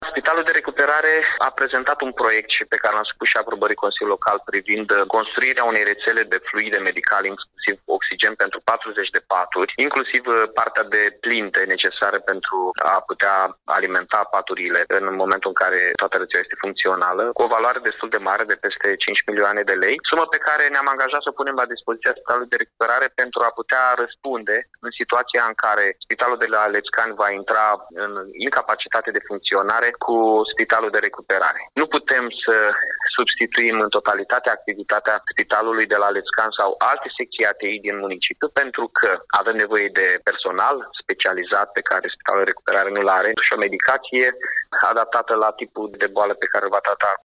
Primarul Iașiului, Mihai Chirica, a precizat că, în momentul în care aceste paturi vor deveni funcționale, spitalul va avea nevoie de transferul unor cadre medicale specializate.